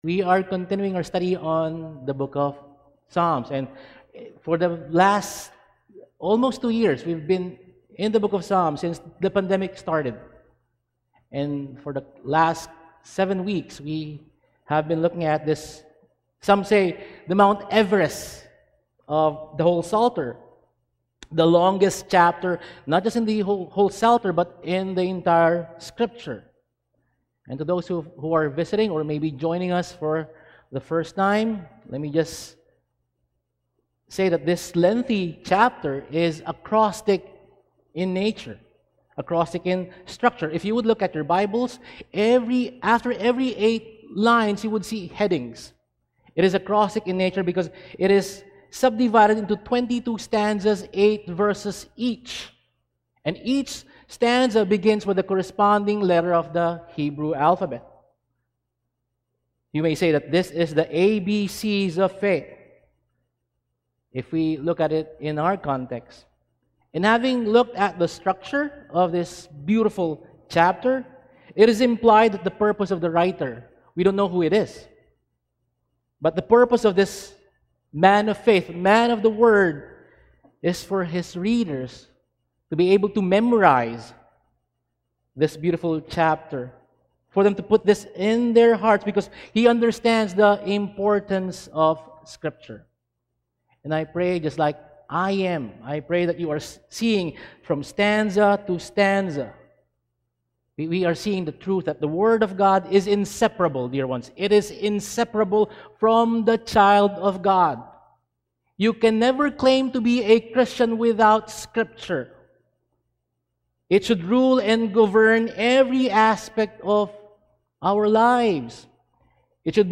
Psalm 119:57–64 Service: Sunday Sermon Outline